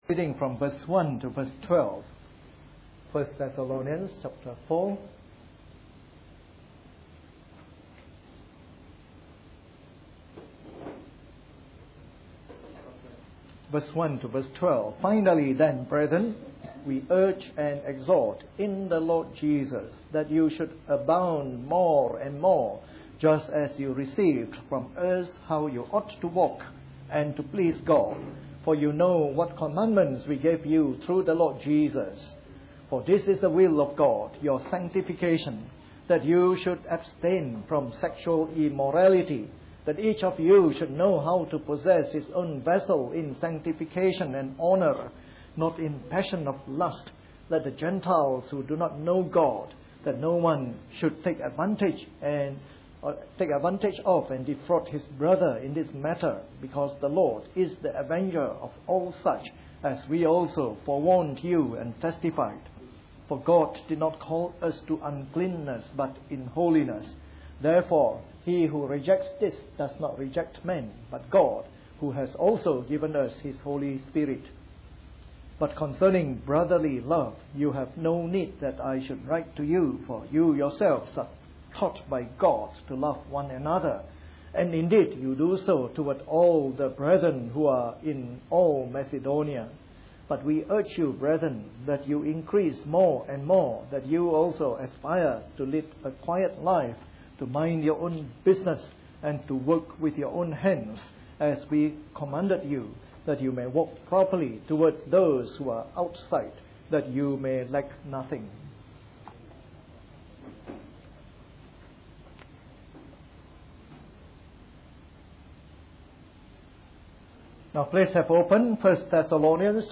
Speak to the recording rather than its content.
A sermon in the morning service from our series on 1 Thessalonians.